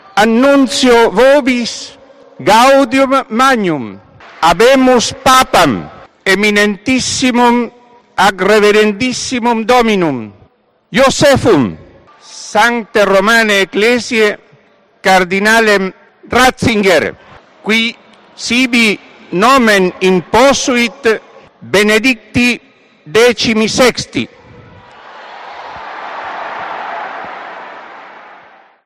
Once días después, el 19 de abril de 2005, en el segundo día de cónclave y tras cuatro rondas de votaciones, Josep Ratzinger fue elegido su sucesor, como anunció el cardenal protodiácono Jorge Medina Estévez.
ctv-noe-jorge-medina-habemus-papam